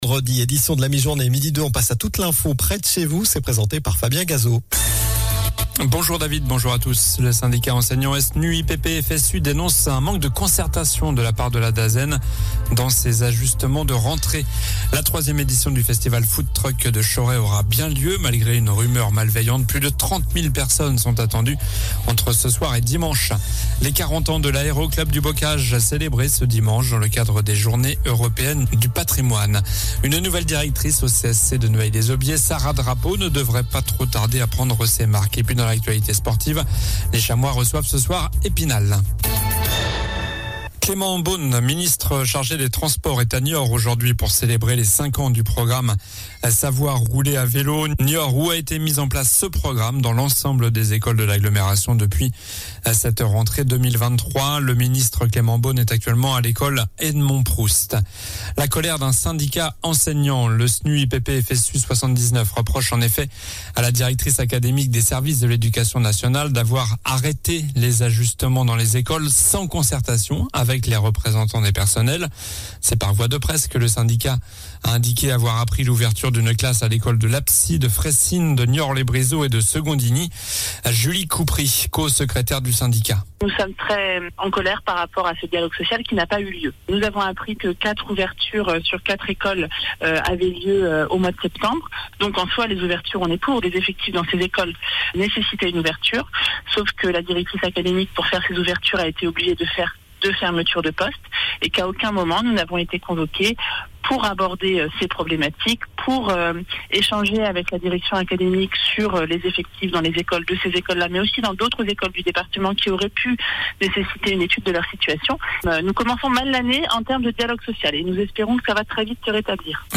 Journal du vendredi 15 septembre (midi)